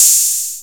808-OpenHiHats13.wav